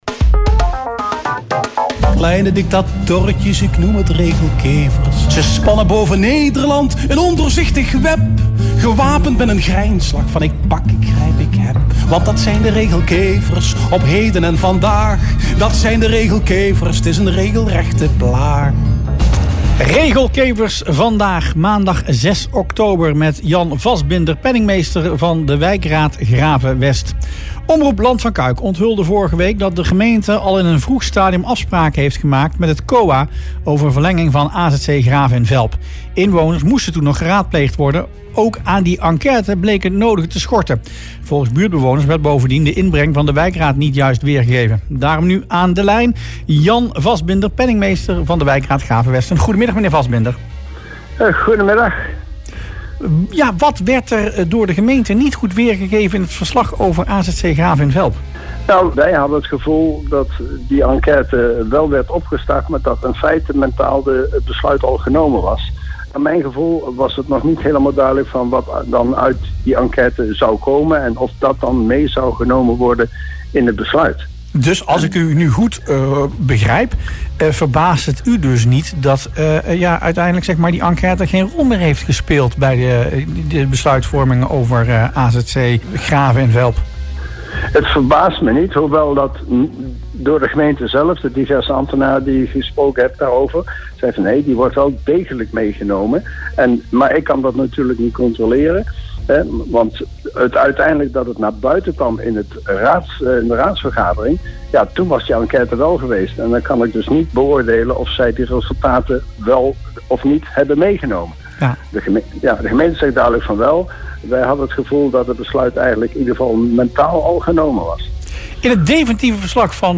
in Rustplaats Lokkant